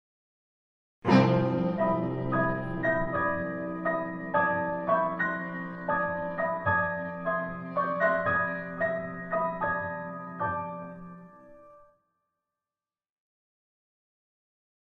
(1999) for clarinet, violin, cello, and piano. 3 minutes.